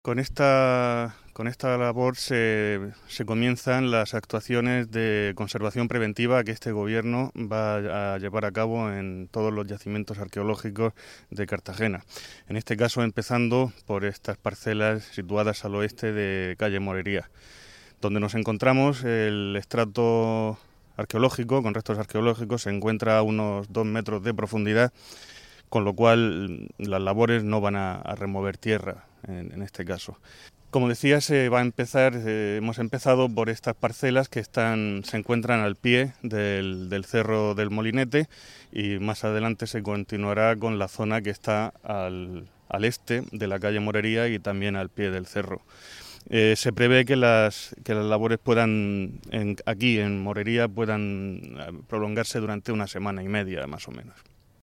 Enlace a Declaraciones de Pablo Braqueháis